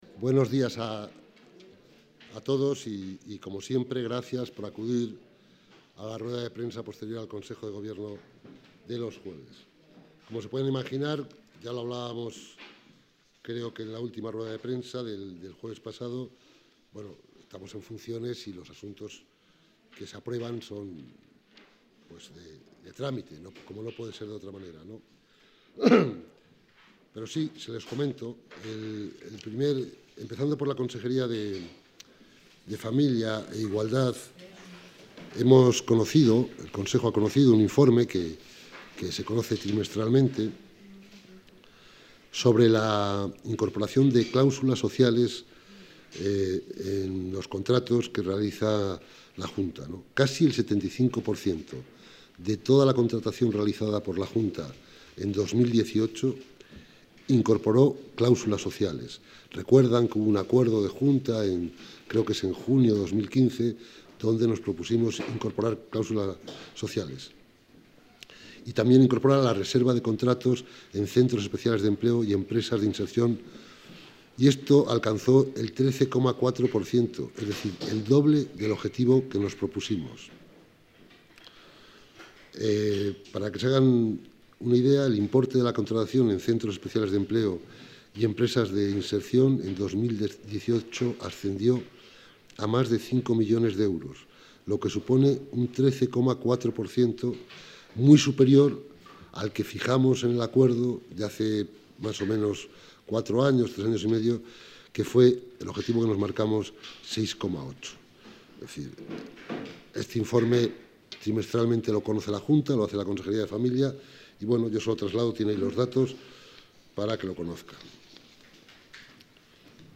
Rueda de prensa tras el Consejo de Gobierno.